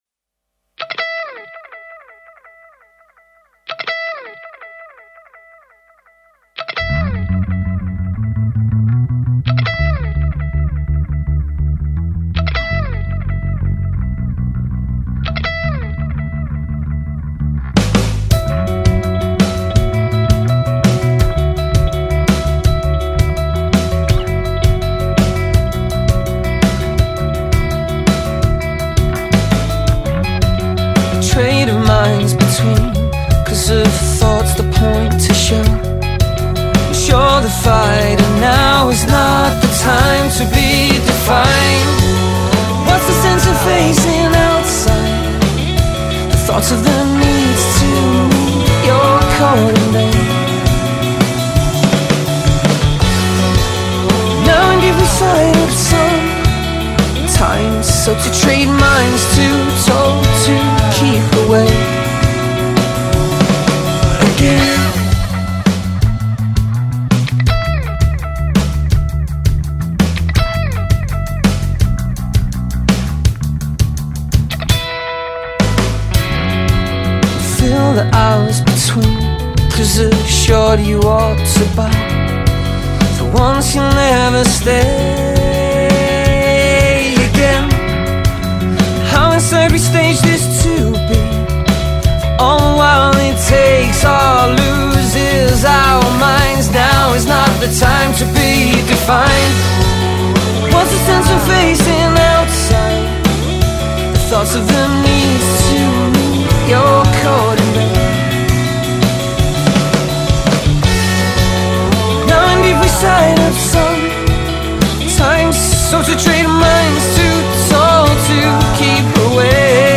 indie-alternative band